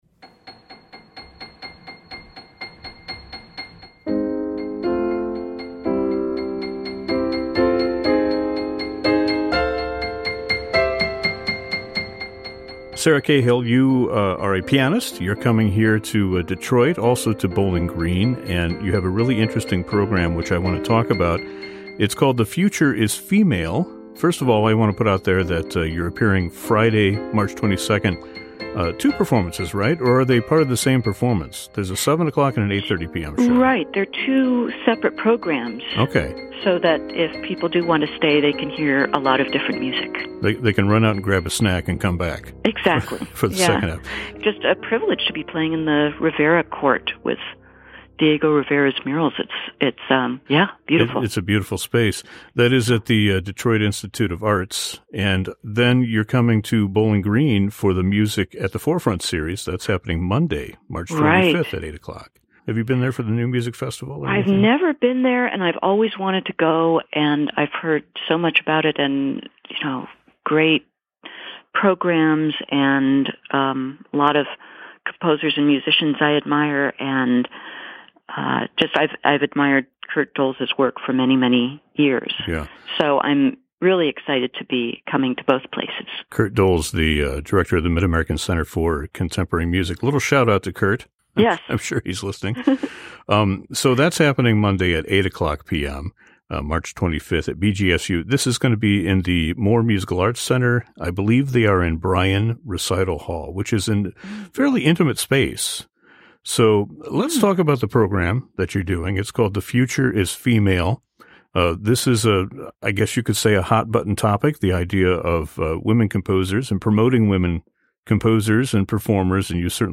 Sarah joins us for a conversation about the program and her longtime championship of music by living composers.